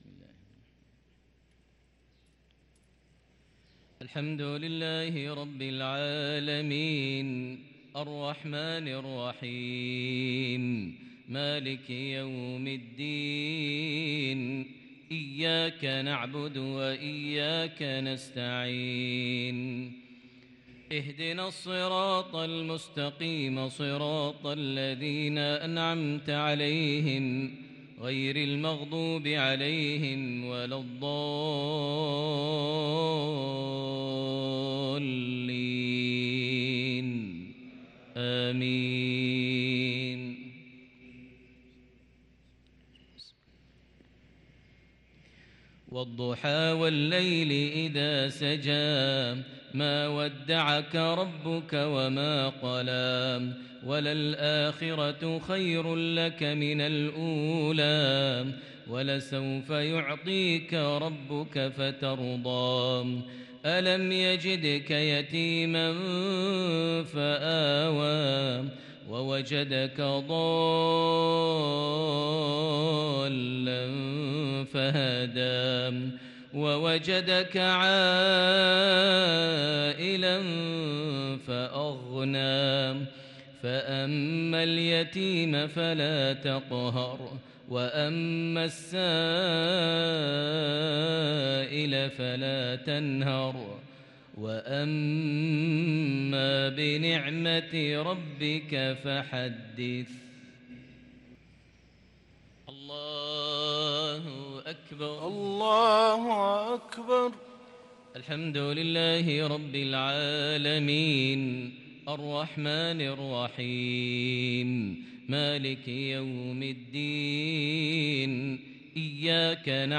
صلاة المغرب للقارئ ماهر المعيقلي 29 ذو الحجة 1443 هـ
تِلَاوَات الْحَرَمَيْن .